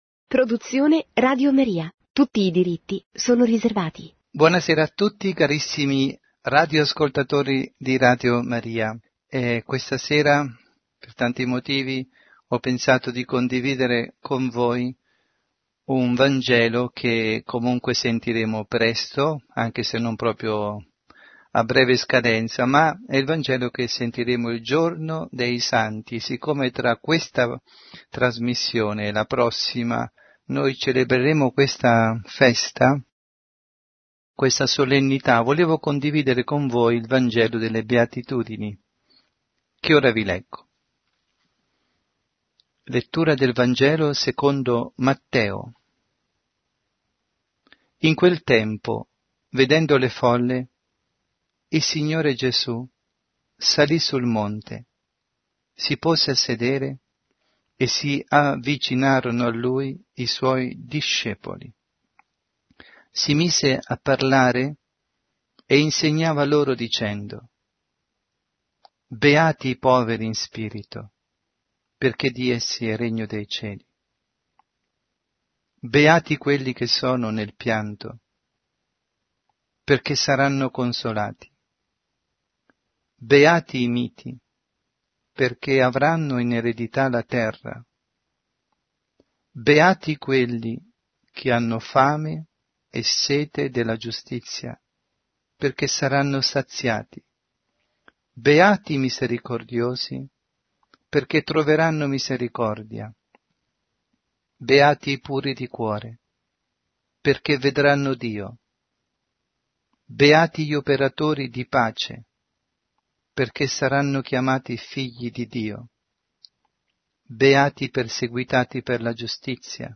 Catechesi